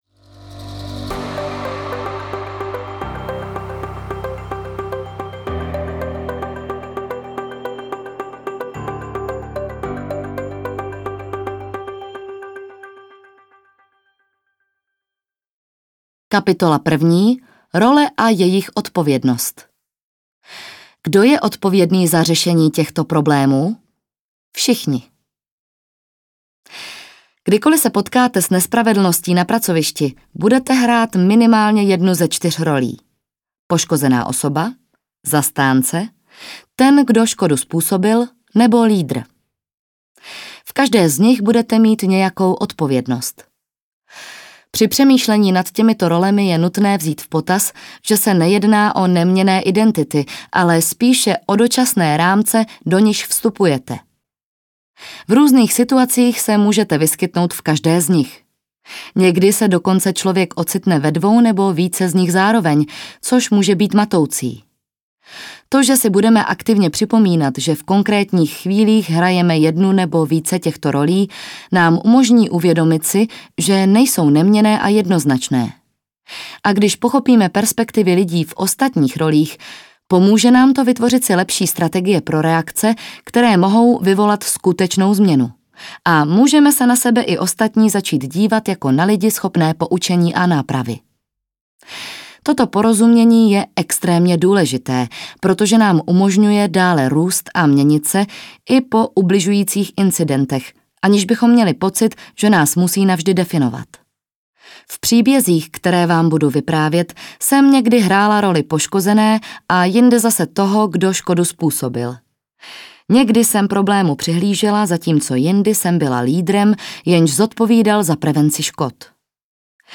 Audiokniha Férová práce